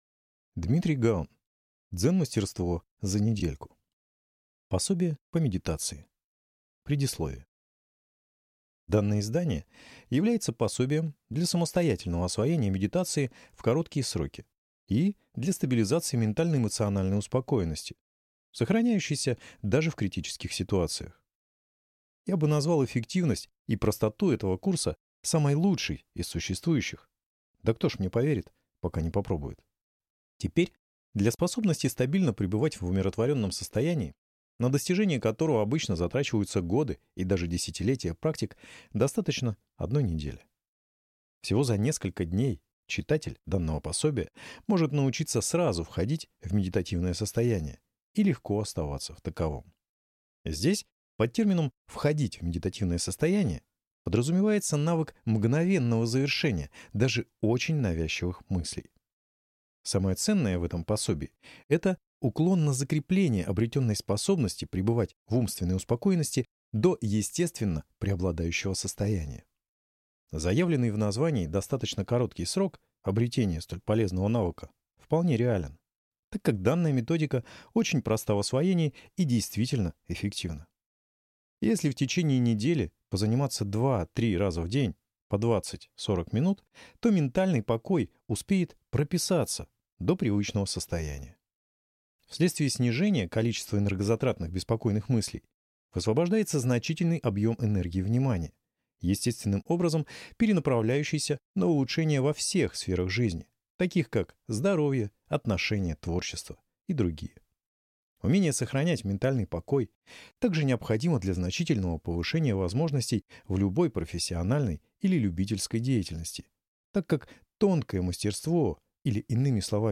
Аудиокнига Дзенмастерство за недельку | Библиотека аудиокниг